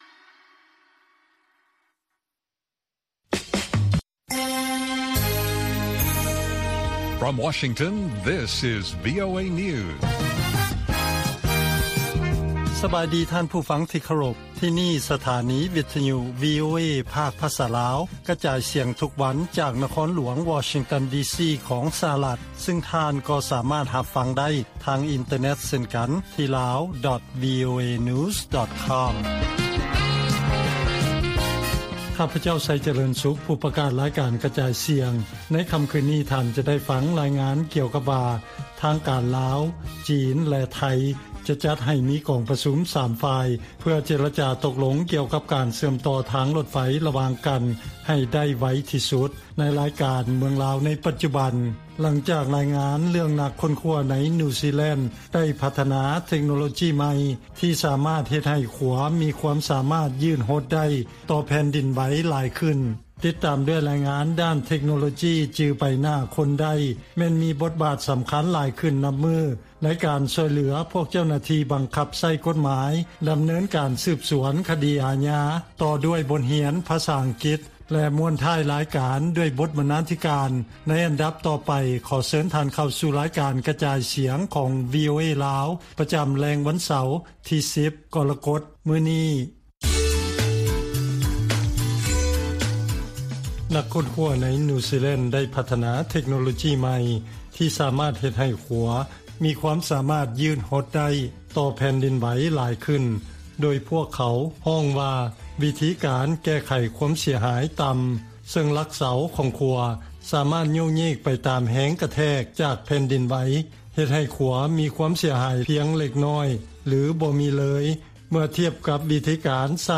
ລາຍການກະຈາຍສຽງຂອງວີໂອເອ ລາວ: ທາງການ ລາວ, ຈີນ, ໄທ ຈະຈັດໃຫ້ມີກອງປະຊຸມ 3 ຝ່າຍເພື່ອເຈລະຈາຕົກລົງກ່ຽວກັບການເຊື່ອມຕໍ່ທາງລົດໄຟ
ວີໂອເອພາກພາສາລາວ ກະຈາຍສຽງທຸກໆວັນ.